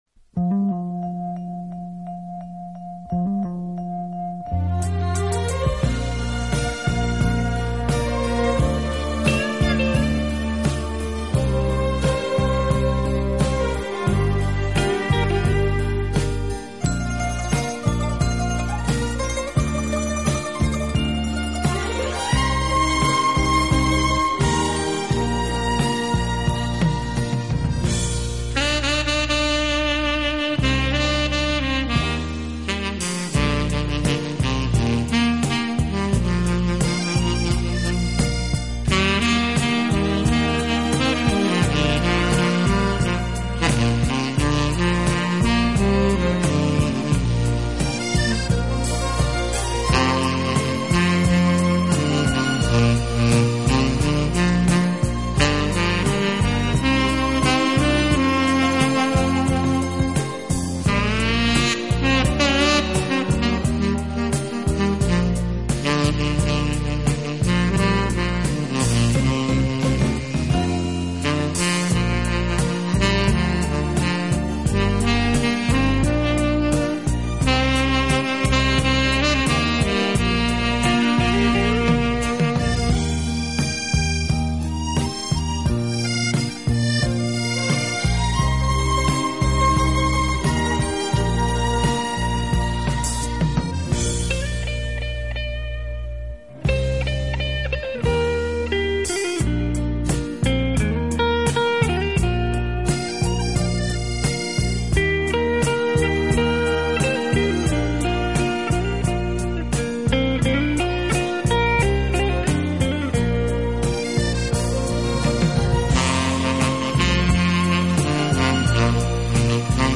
轻音乐
低音渾厚的音色见长